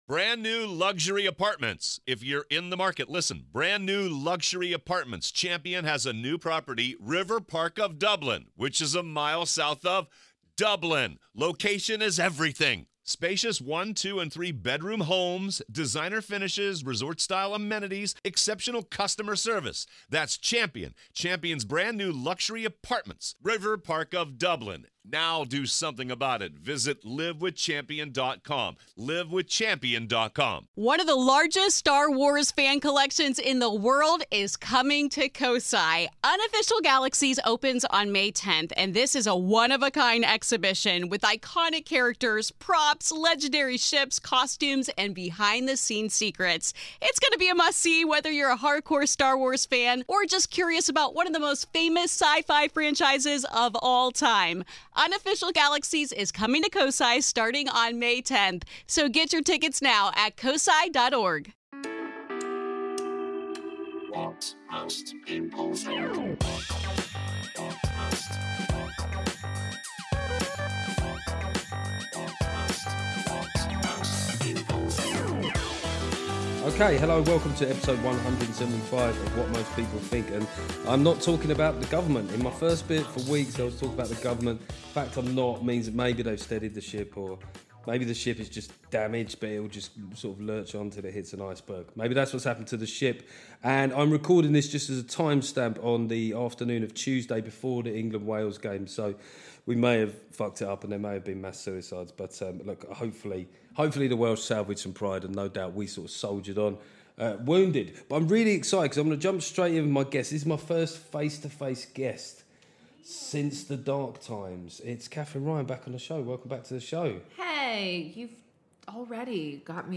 It was an absolute pleasure to have Katherine Ryan back co-hosting the show.